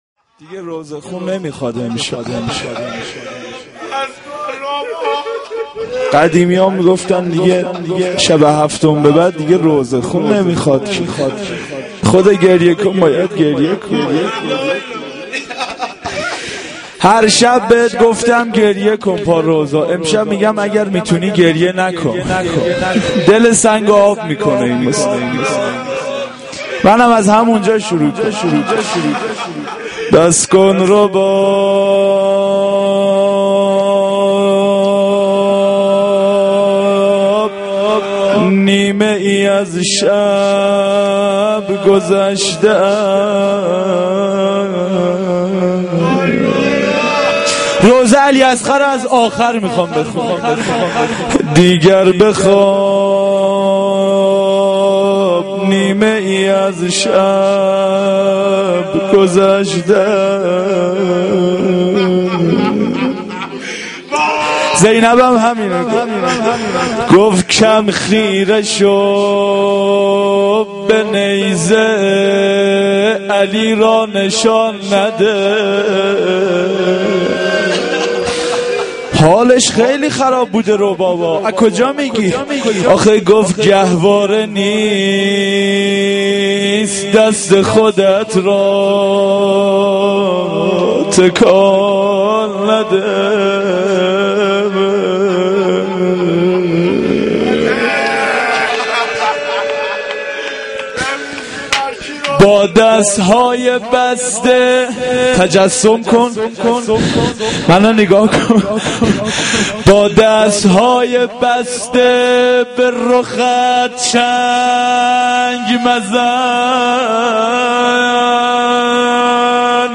sh-7-moharram-92-roze.mp3